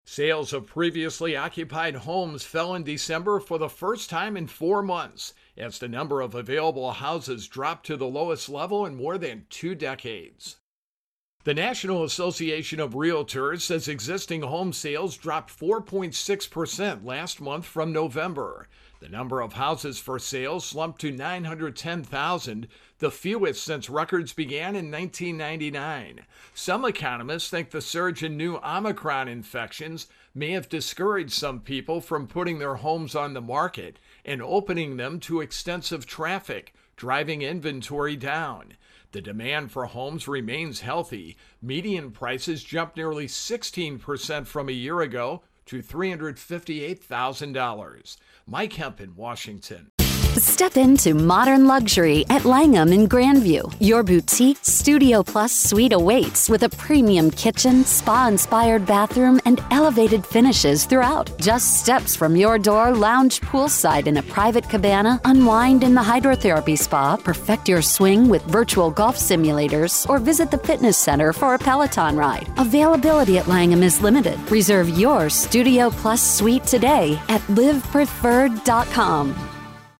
Intro and voicer for Home Sales